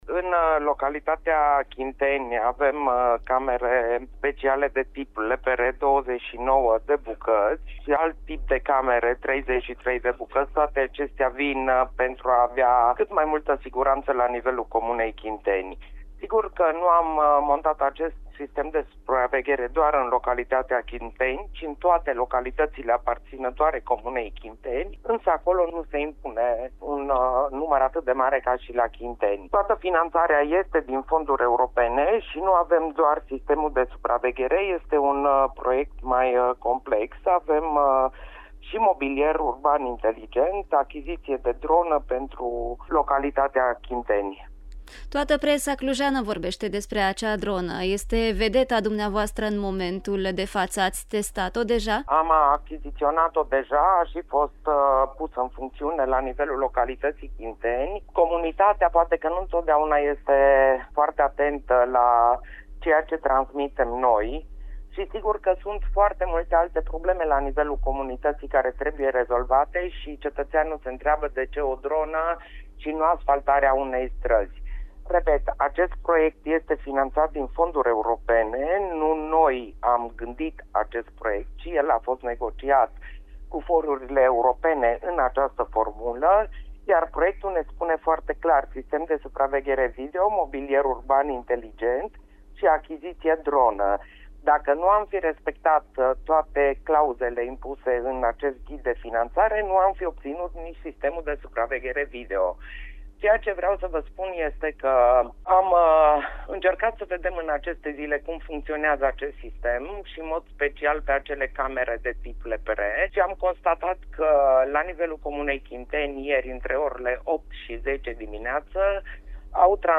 Primar-Chinteni-19-decembrie.mp3